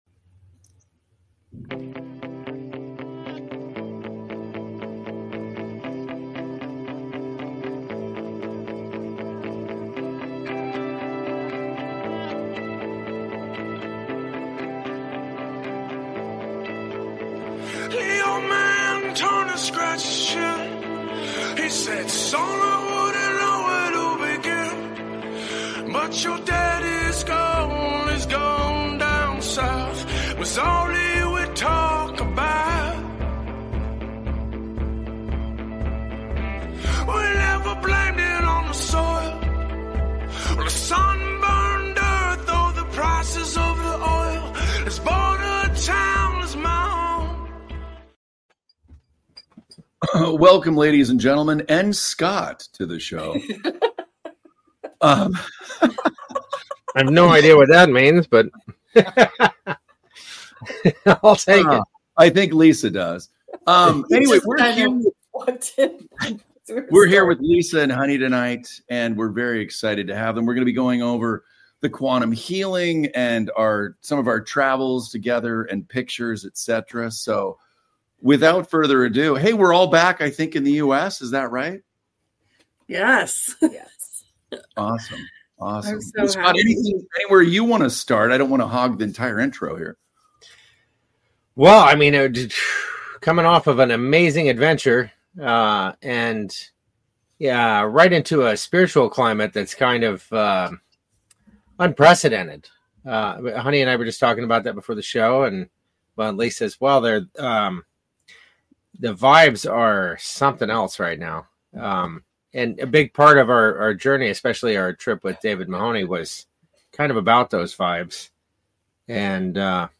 The text is a conversation between a group of friends who recently returned from a trip to Ireland.